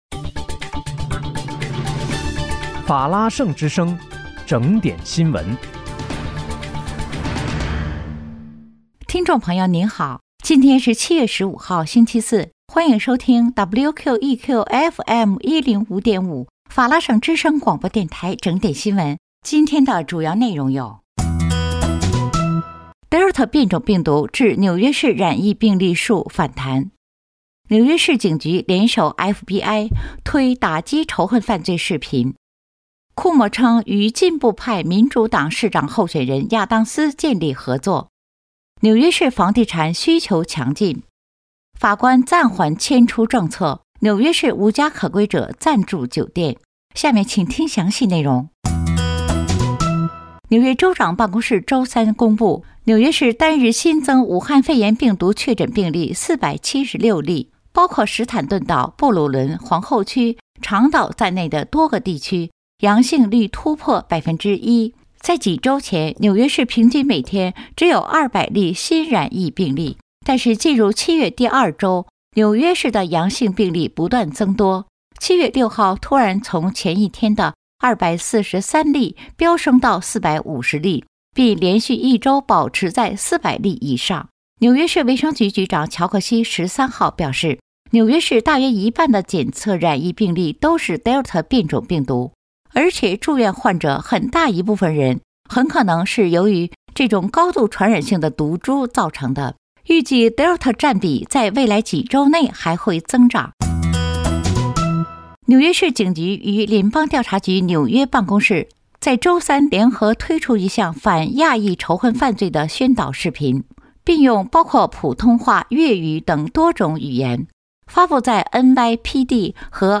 7月15日（星期四）纽约整点新闻